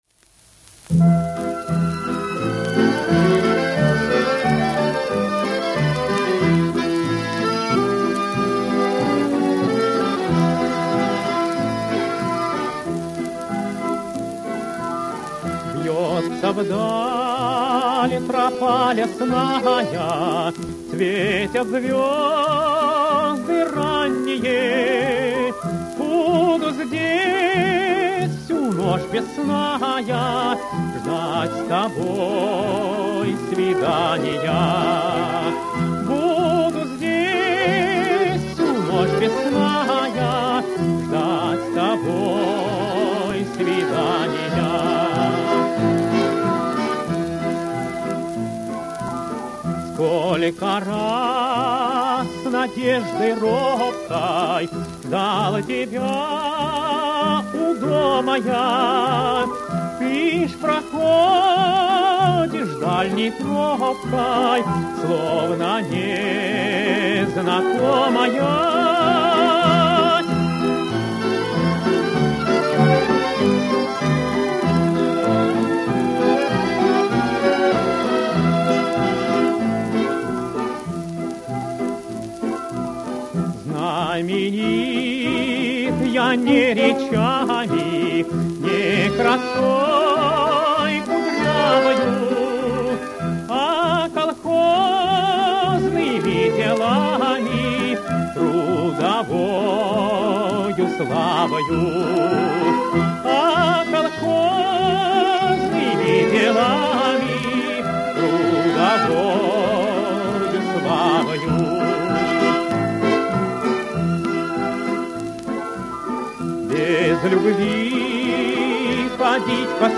Запись с пластинки